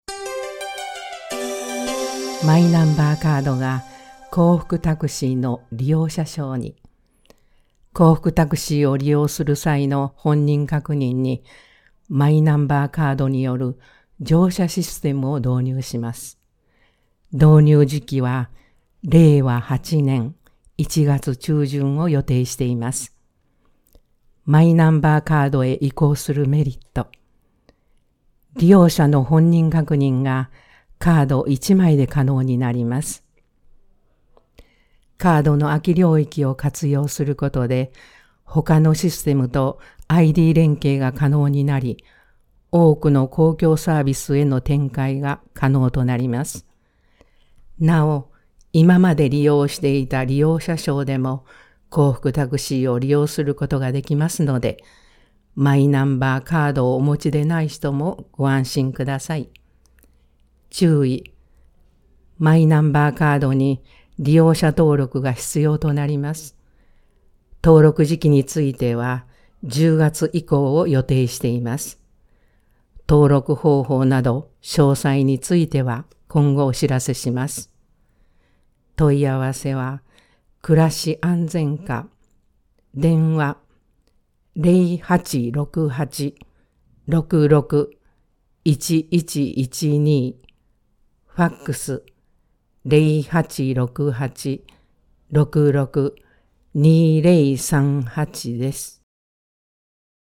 声の広報（広報紙の一部を読み上げています）